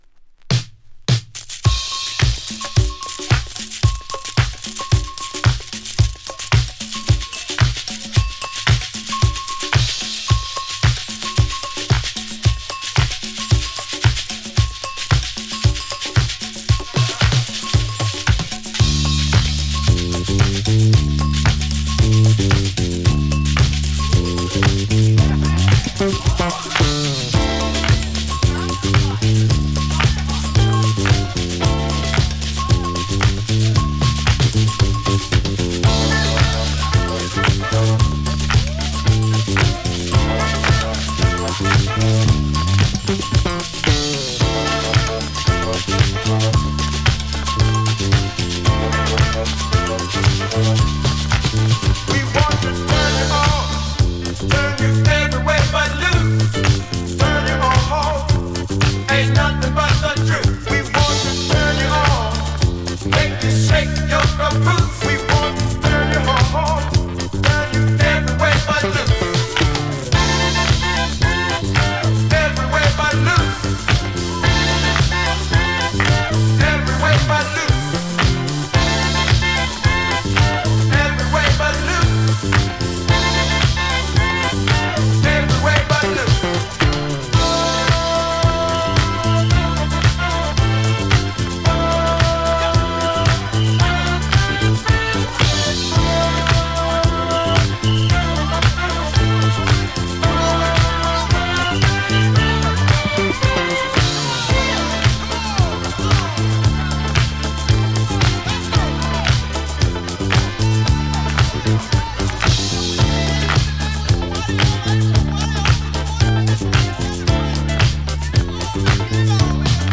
> DANCE CLASSICS/GARAGE